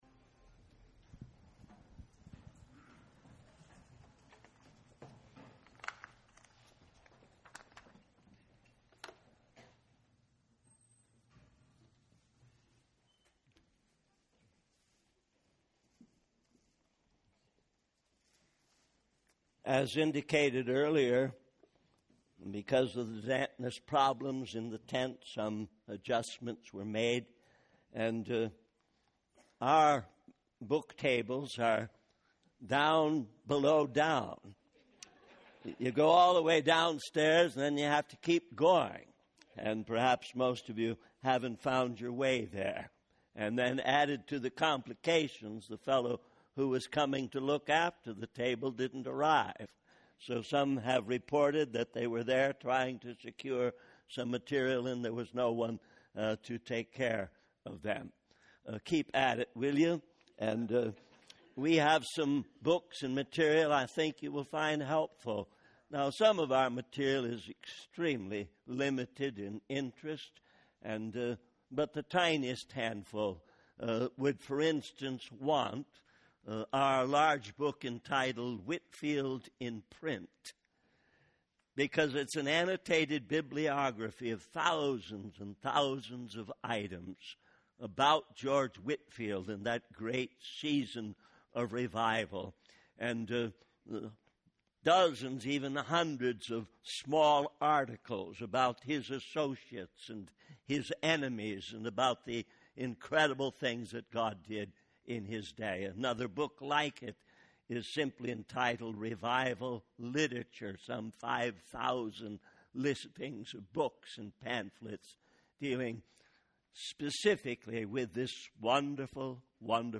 In this sermon, the speaker emphasizes the importance of preaching the word of God with passion and preparation.